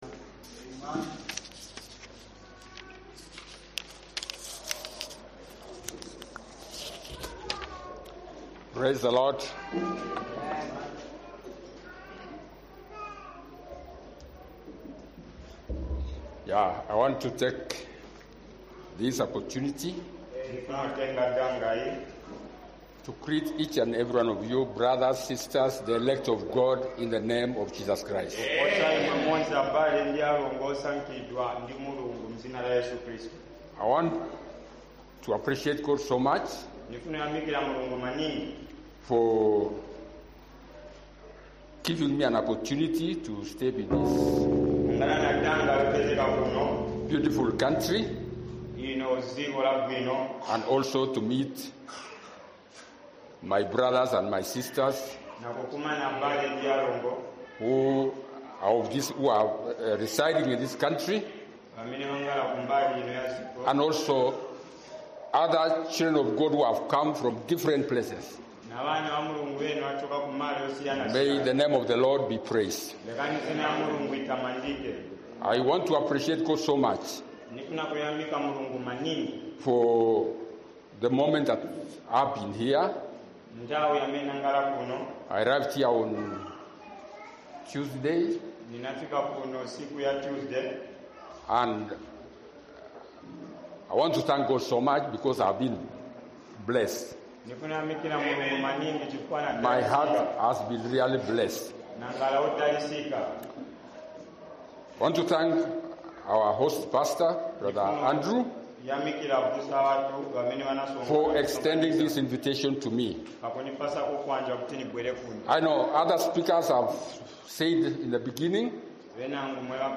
Daily Devotion Convention 2024